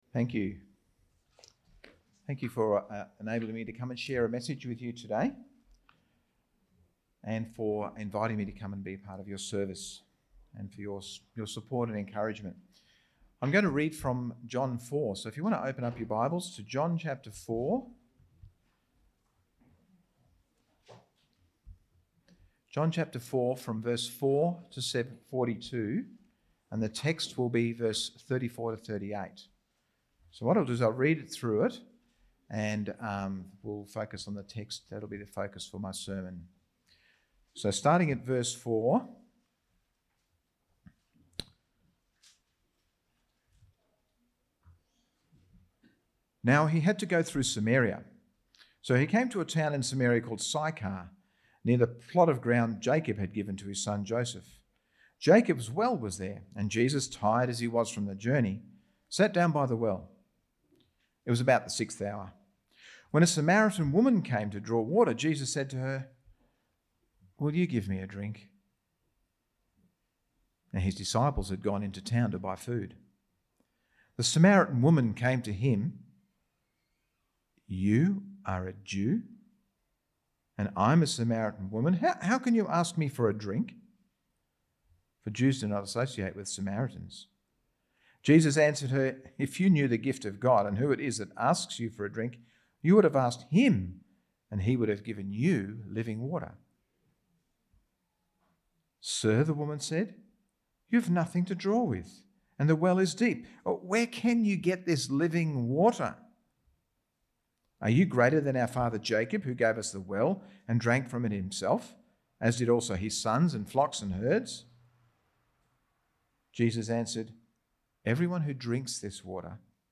Topical Sermon
Service Type: Sunday Morning